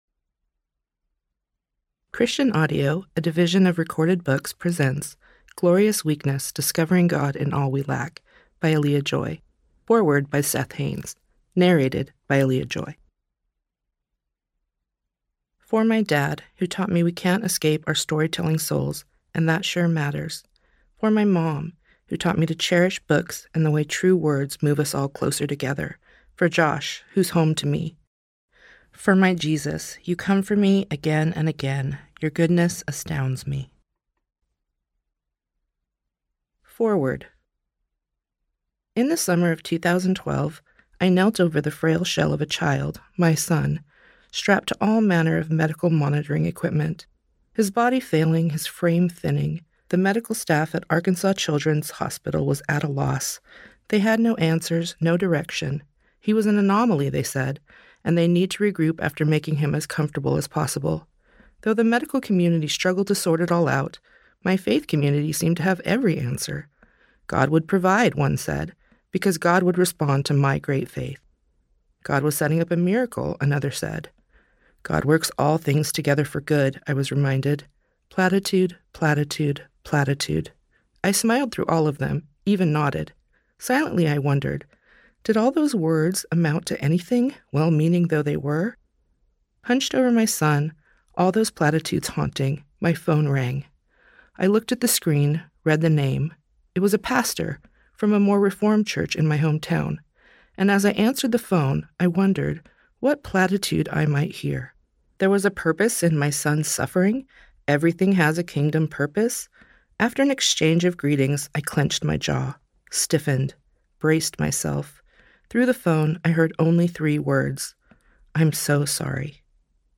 Glorious Weakness: Discovering God in All We Lack Audiobook
– Unabridged